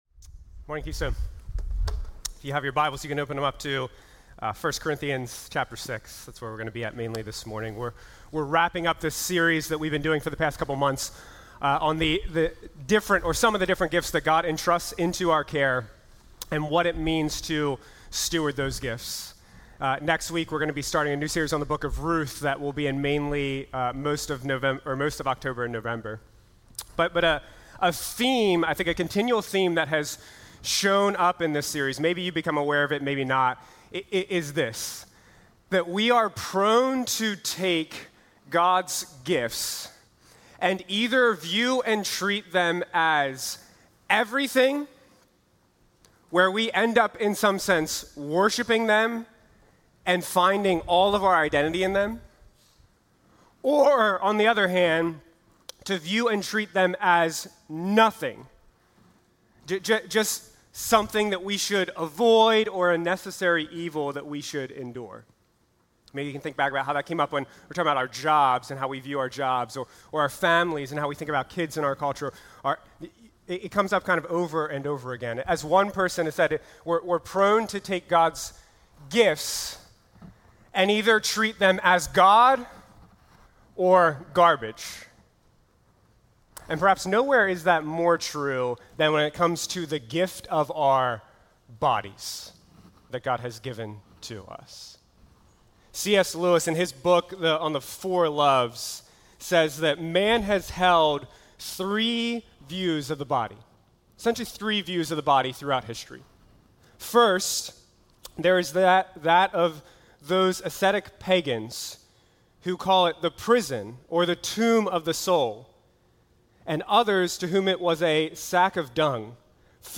These are sermons delivered during the Sunday morning worship services of Keystone Church, an Evangelical Free Church in Paradise, PA, USA.